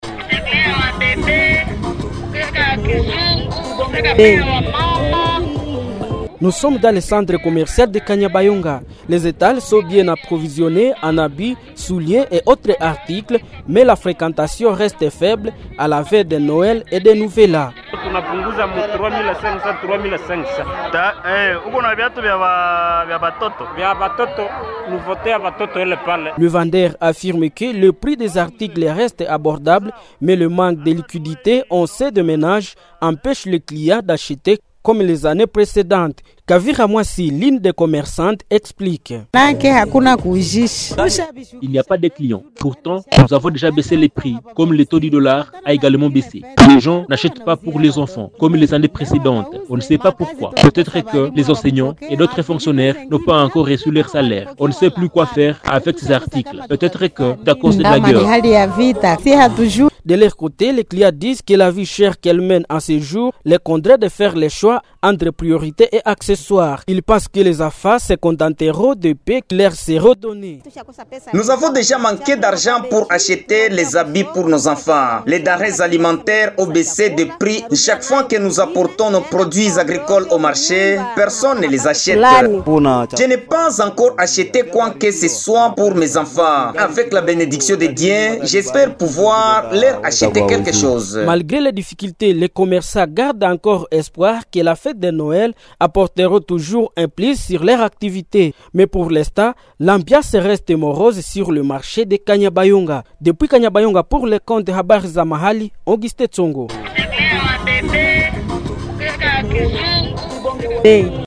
REPORTAGE-FR-SUR-PREPATIF-NOEL.mp3